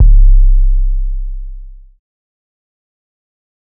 TC 808 1.wav